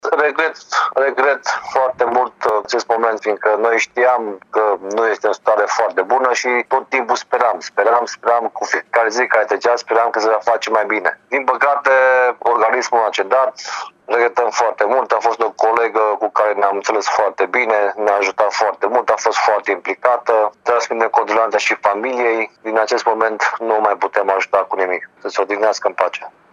Subprefectul de Timiș, Mircea Băcală, spune că Elena Popa a fost o colegă foarte bună, care a ajutat mult în activitatea instituției.